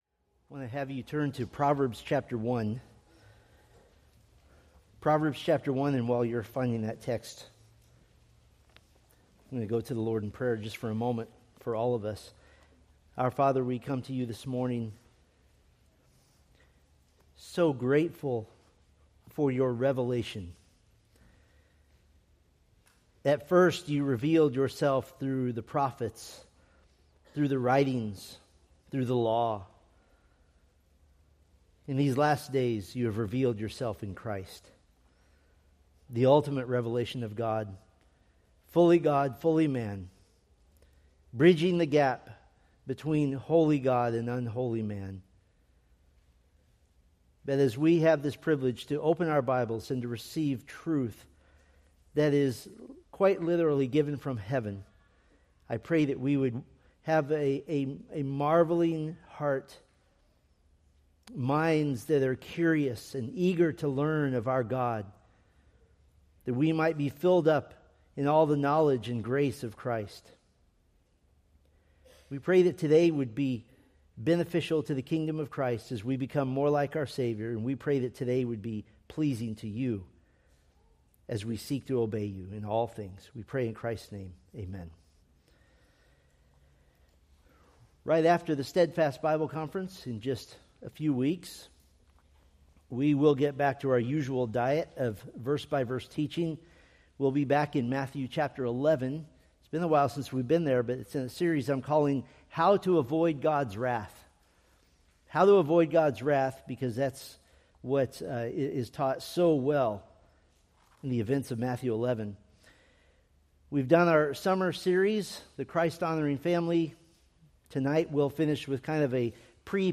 Preached September 7, 2025 from Selected Scriptures